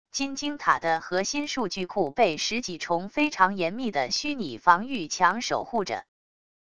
金晶塔的核心数据库被十几重非常严密的虚拟防御墙守护着wav音频